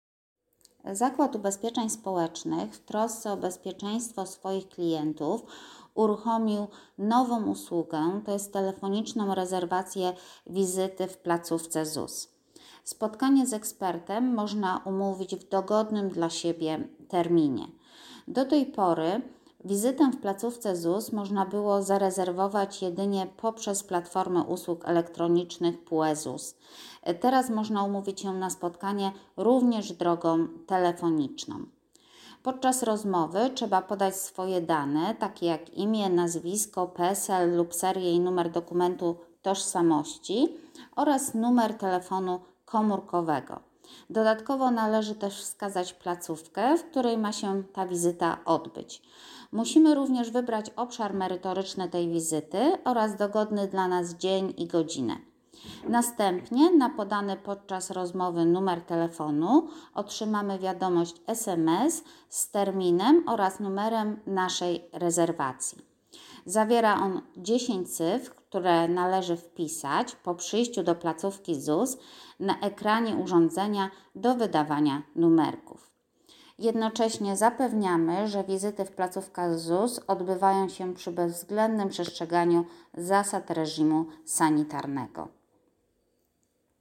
Nagranie głosowe przedstawiające treść komunikatu prasowego